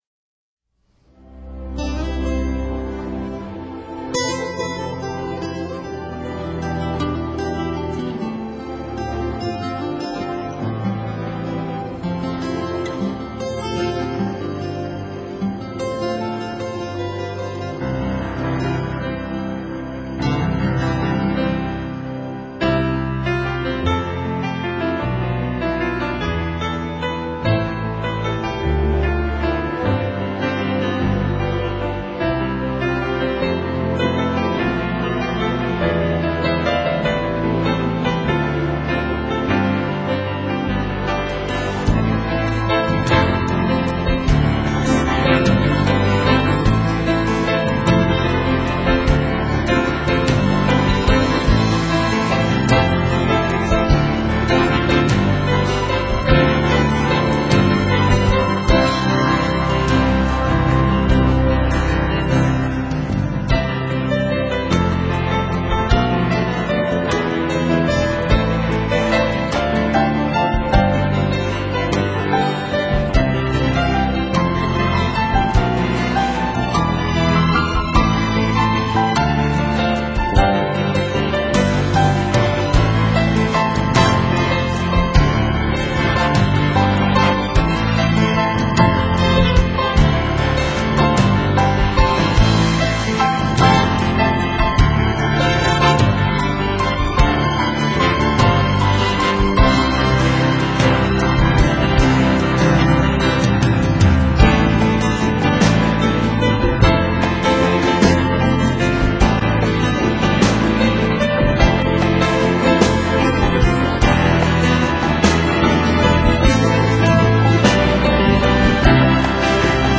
所有的乐器和旋律都交融的非常好，搭配的也是柔情似水般。